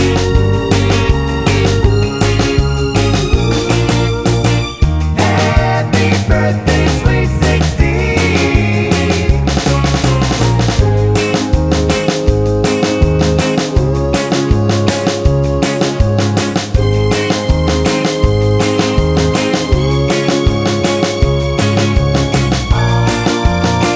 No Two Part Harmony Pop (1960s) 2:42 Buy £1.50